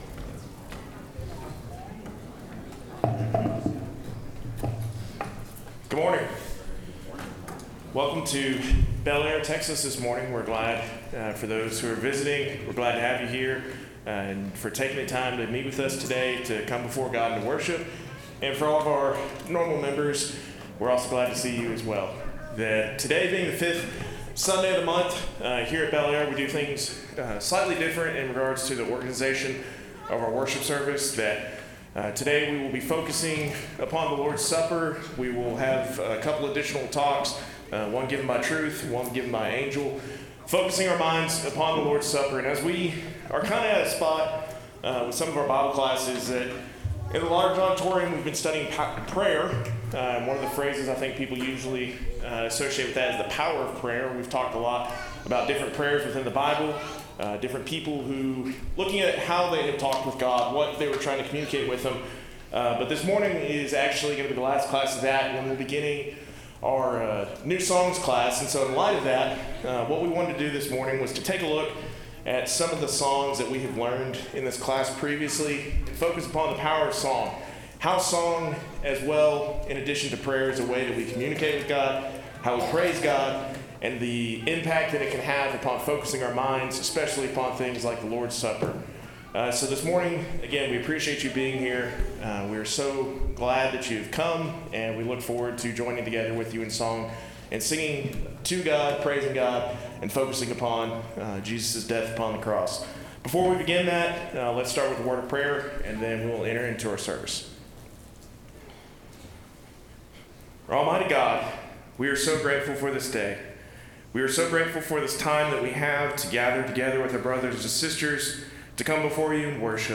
Singing and Prayer – Songs of Prayer, Praise, Power and Joy – By Bellaire...